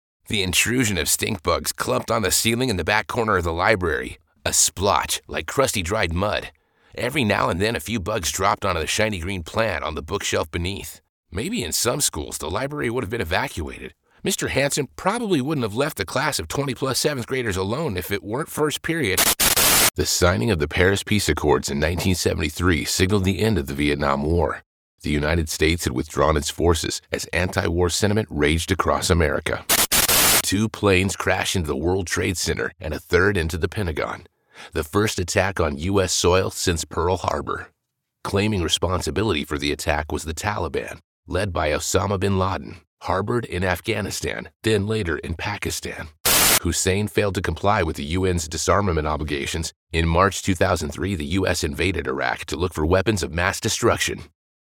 Englisch (Amerikanisch)
Natürlich, Unverwechselbar, Zugänglich, Vielseitig, Freundlich
Erklärvideo